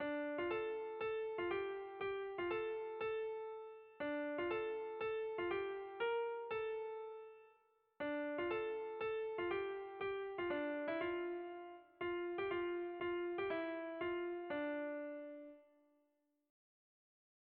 Dantzakoa
Lauko handia (hg) / Bi puntuko handia (ip)
A-B